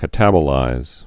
(kə-tăbə-līz)